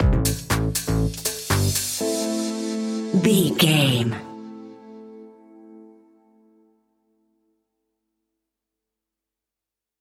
Lively Funk Electro Stinger.
Aeolian/Minor
groovy
uplifting
energetic
bass guitar
strings
electric piano
synthesiser
drums
funky house